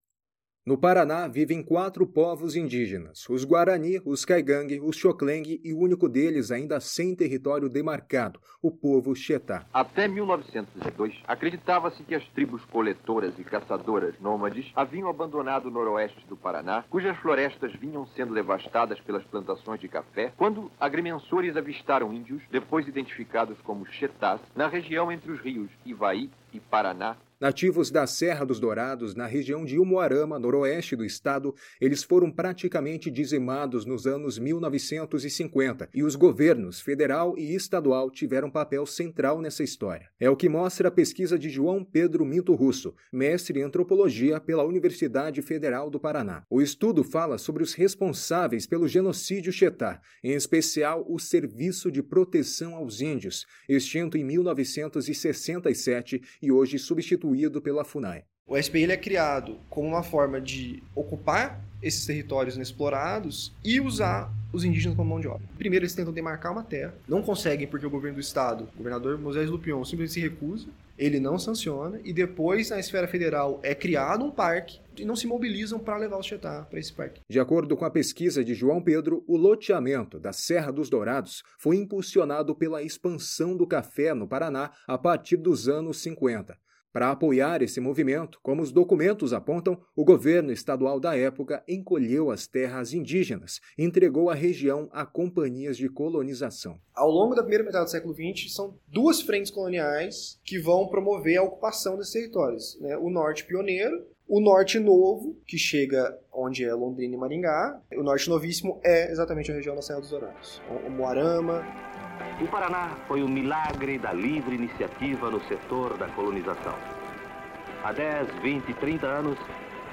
[SONORA]